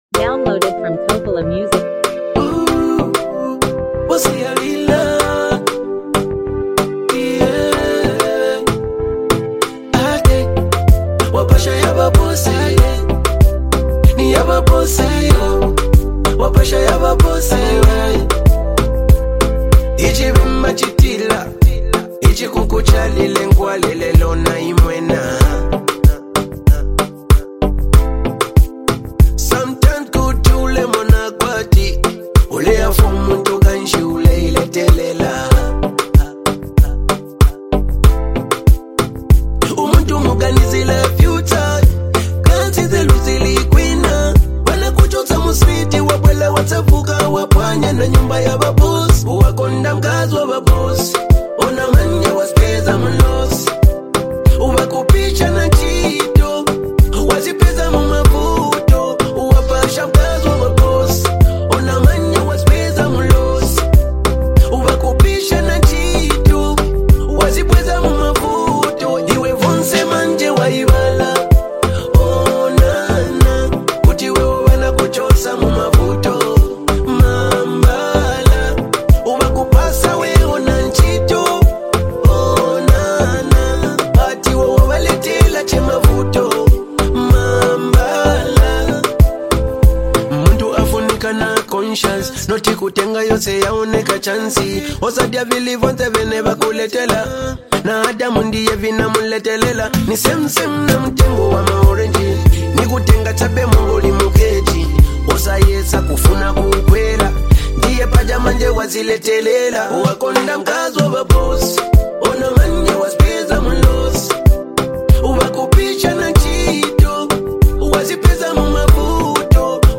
is an energetic and motivational song